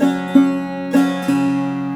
SAROD2    -R.wav